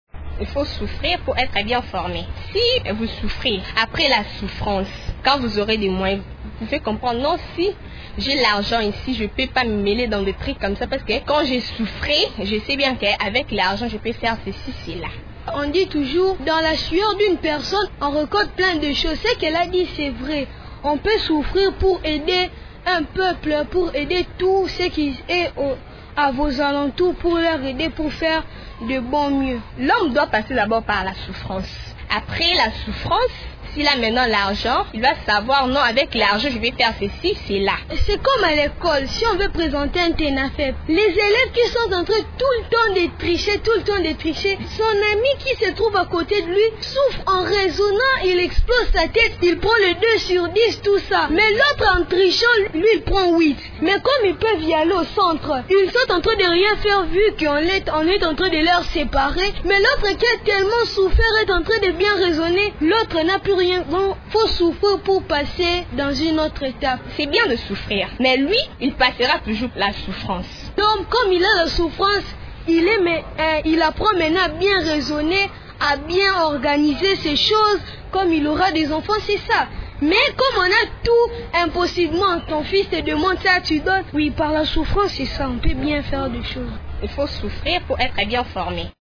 C’est l’avis des enfants interviewés au cours de cette émission.